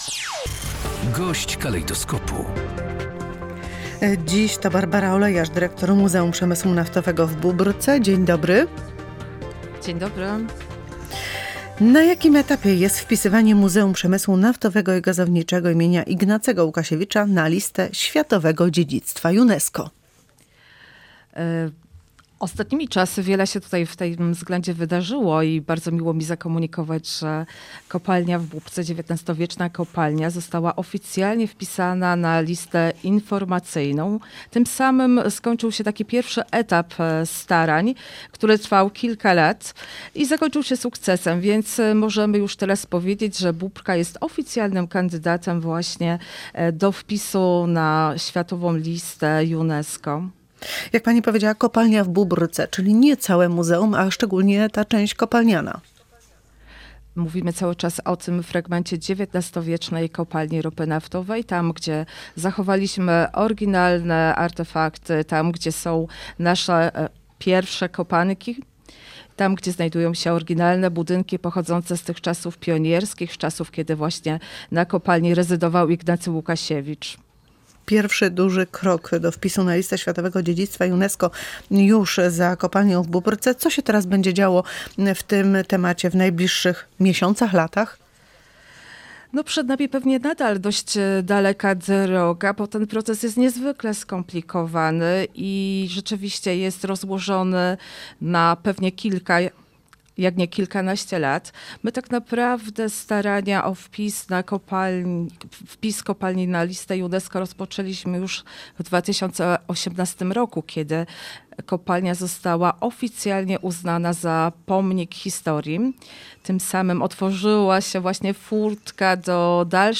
Gość dnia • Kopalnia ropy naftowej w Bóbrce znalazła się na liście informacyjnej UNESCO – to pierwszy krok na drodze do uzyskania prestiżowego wpisu na